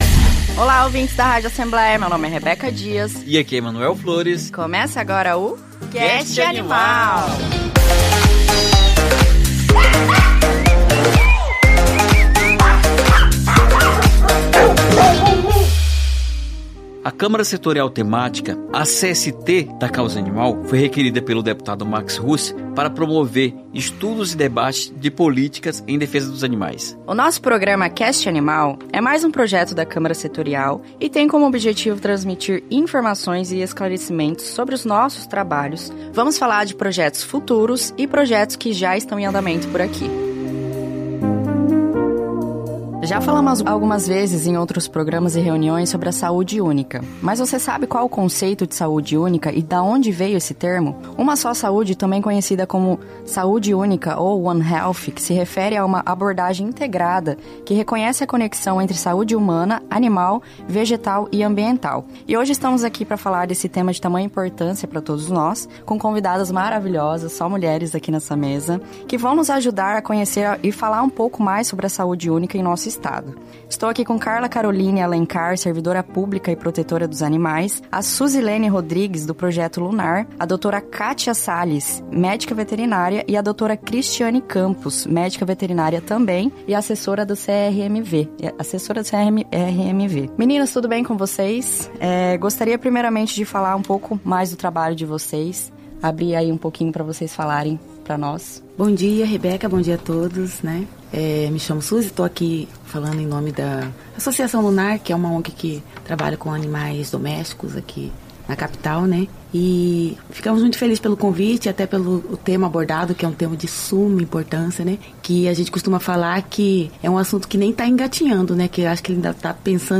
A “Uma Só Saúde”, também conhecida como “Saúde Única”, é a tradução do termo em inglês “One Health”, que se refere a uma abordagem integrada que reconhece a conexão entre a saúde humana, animal, vegetal e ambiental. E no 4º episódio do CastAnimal vamos falar sobre esse tema de tamanha importância para todos nós, com convidadas que vão nos ajudar a conhecer e falar um pouco mais sobre a saúde única em nosso Estado.